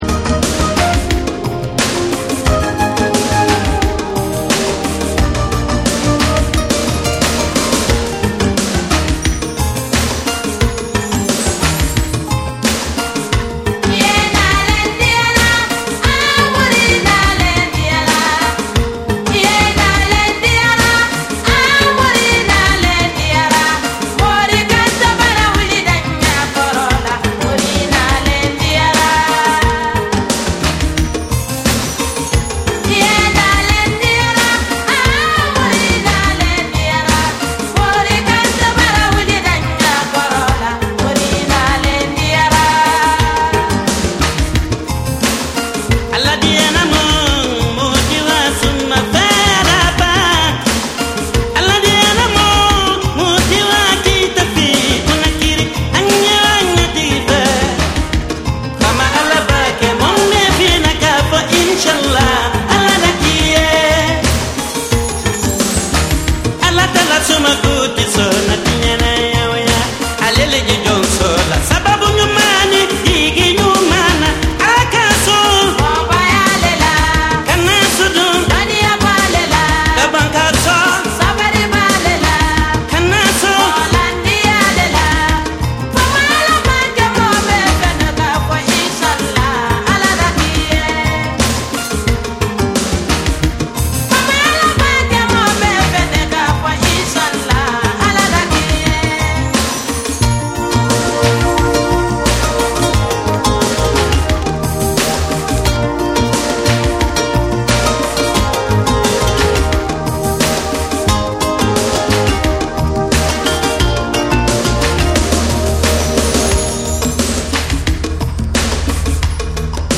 伝統的なアフリカ音楽とエレクトロ・ビートを融合させたハイブリッドなサウンドを展開。
WORLD / TECHNO & HOUSE